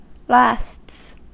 Ääntäminen
Ääntäminen US Haettu sana löytyi näillä lähdekielillä: englanti Käännöksiä ei löytynyt valitulle kohdekielelle. Lasts on sanan last monikko.